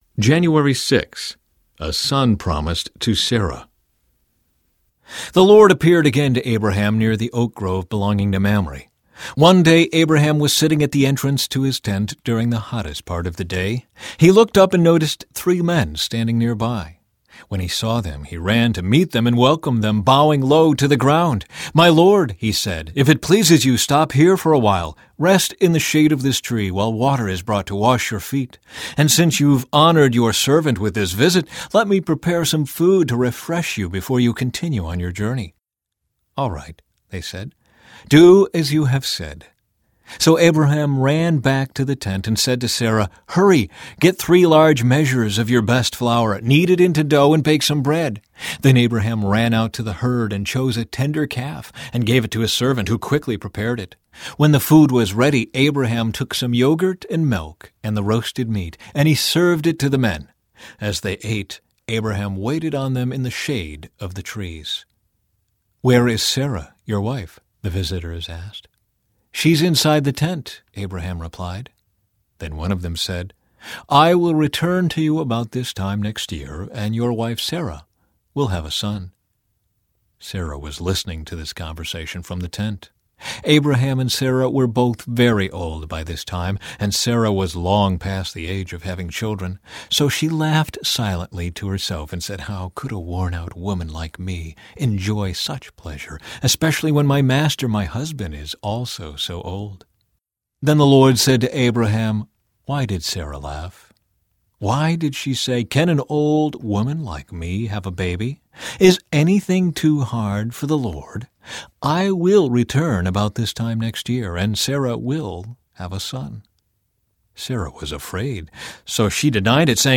Narrator
73.77 Hrs. – Unabridged